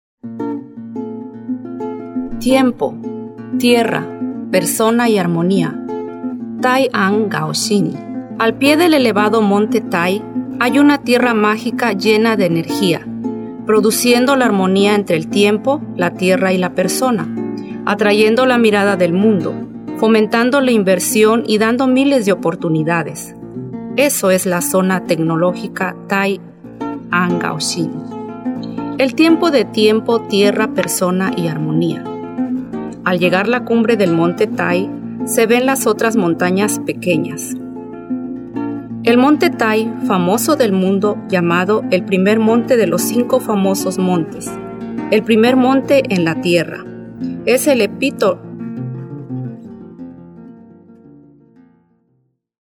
印尼语
印尼女
亲切配音